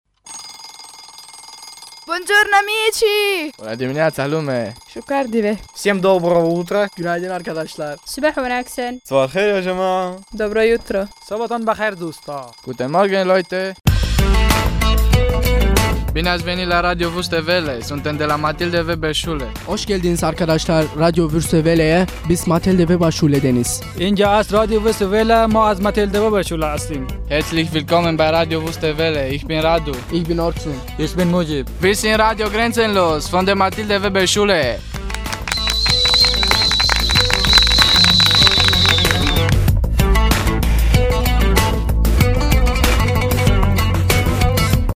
Die Jingles von Radio Grenzenlos erzählen alle eine kurze Geschichte mit Geräuschen, Sprache und Musik mit einem Bezug zum Radioprojekt.
Die interkulturelle Komponente kommt beim dritten Jingle zur Geltung wenn die jugendlichen Redakteure ihre Zuhörenden mehrsprachig begrüßen und sich selbst vorstellen.
45895_guten_morgen_jingle_fertig.mp3